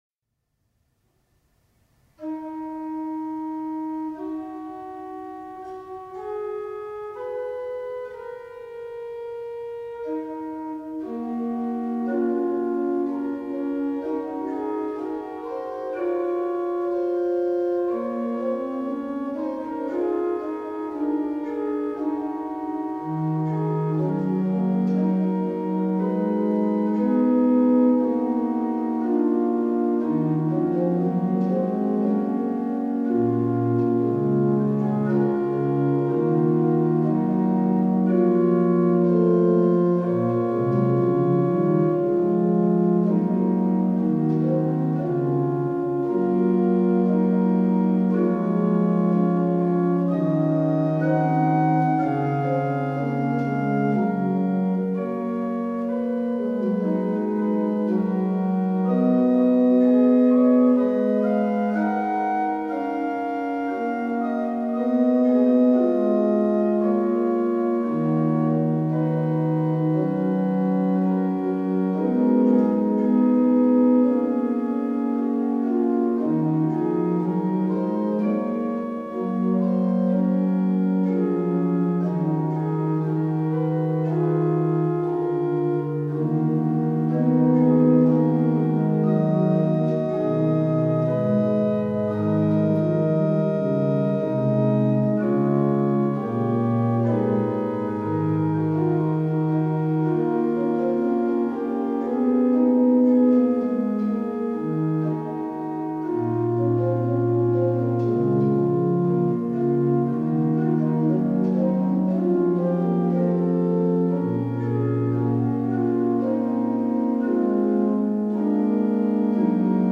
Orgelmuziek voor het hele jaar